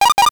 NOTIFICATION_8bit_11_mono.wav